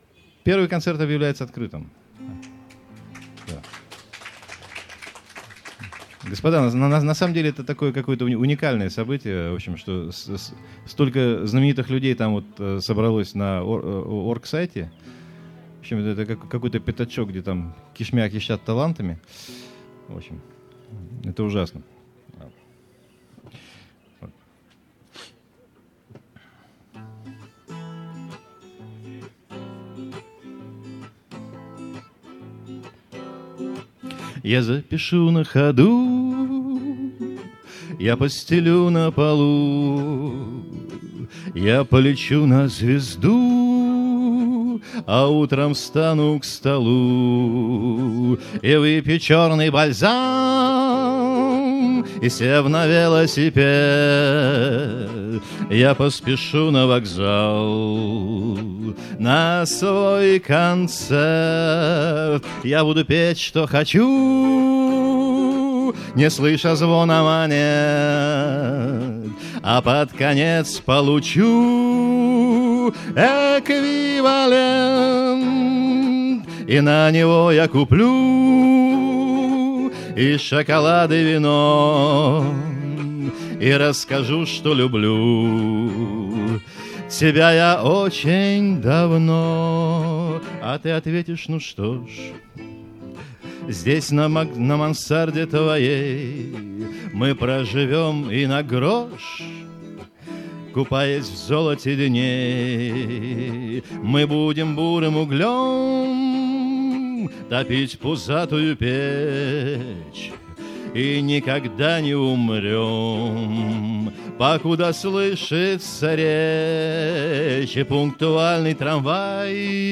2005 - Граненый слёт